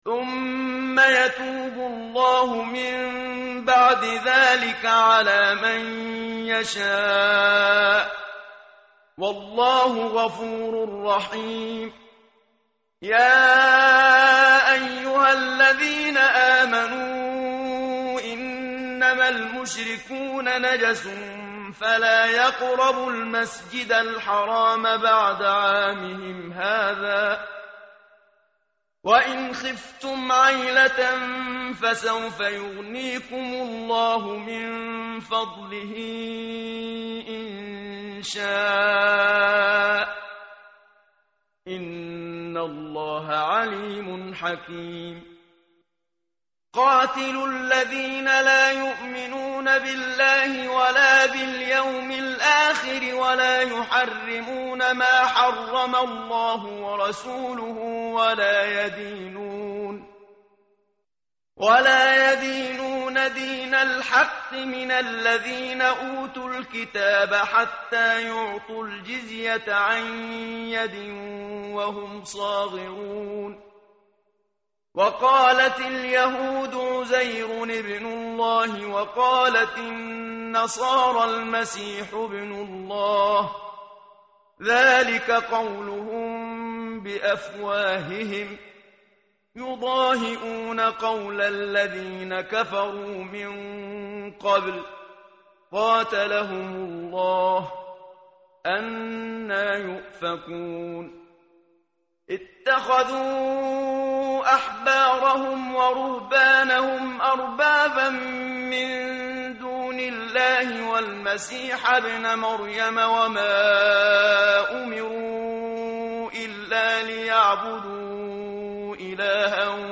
tartil_menshavi_page_191.mp3